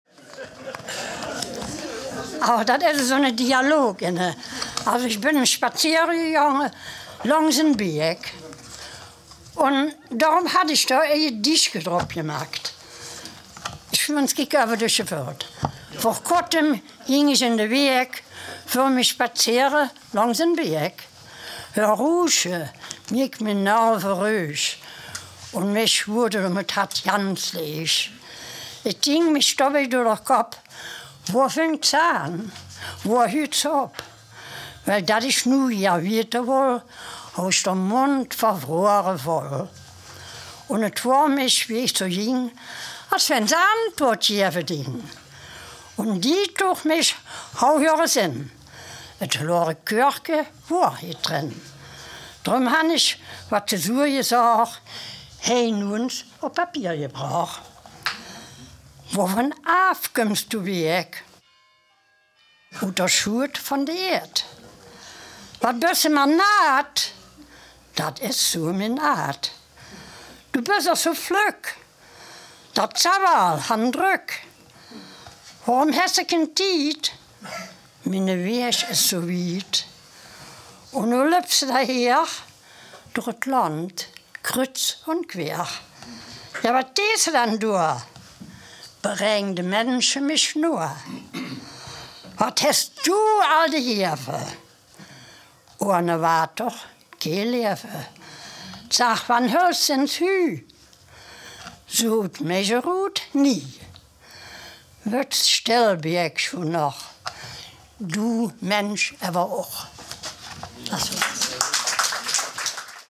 Heinsberger-Kernland-Platt
Gedicht
Dieser Beitrag stammt aus Dremmen.
aus der Sprachregion Heinsberger-Kernland-Platt in der Rubrik Natur